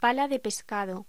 Locución: Pala de pescado
voz